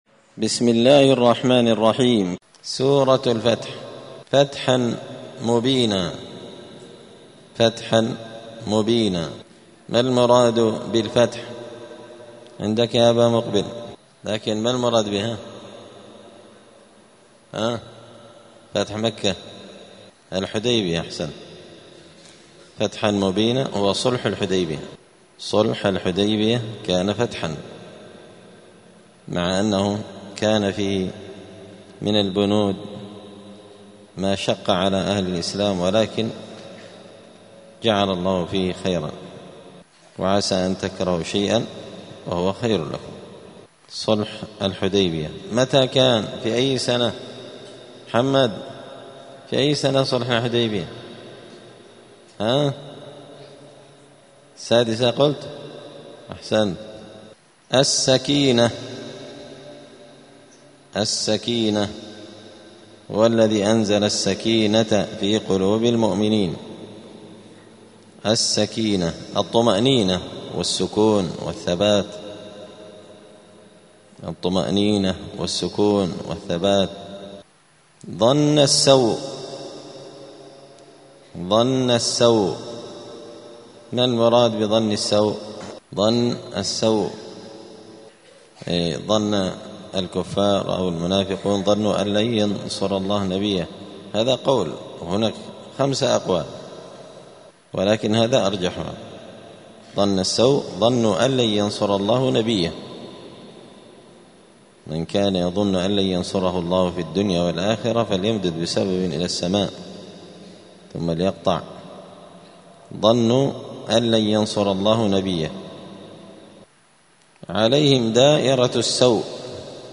الأثنين 25 ربيع الثاني 1446 هــــ | الدروس، دروس القران وعلومة، زبدة الأقوال في غريب كلام المتعال | شارك بتعليقك | 20 المشاهدات